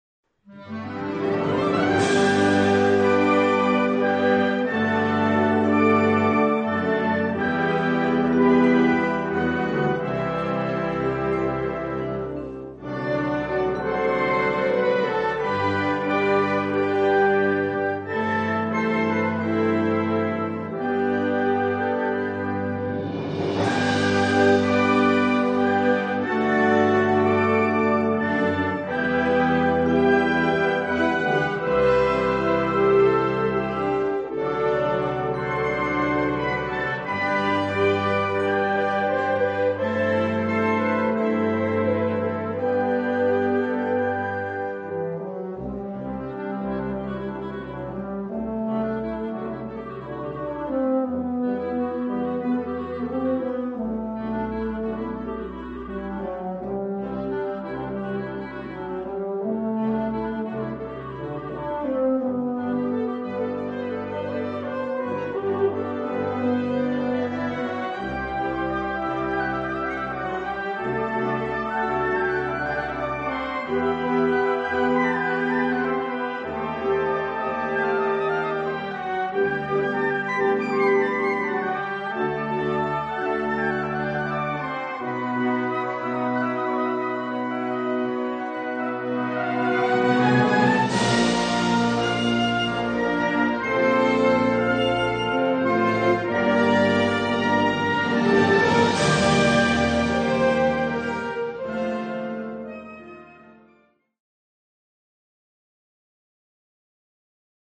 Noten für Blasorchester.